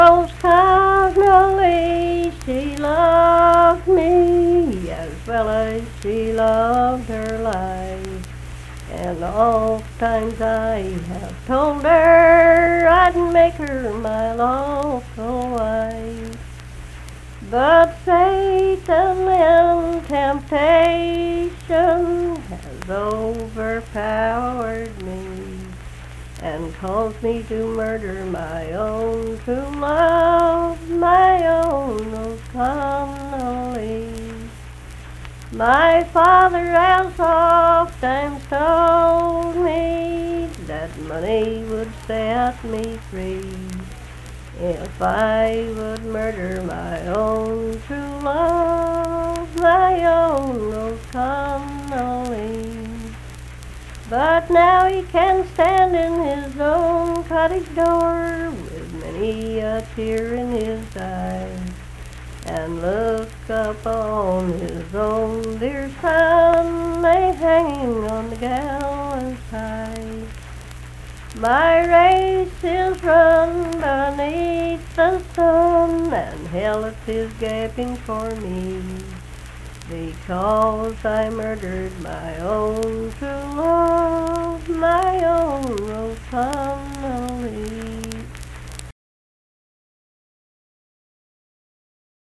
Unaccompanied vocal music performance
Verse-refrain 5(4w/R).
Voice (sung)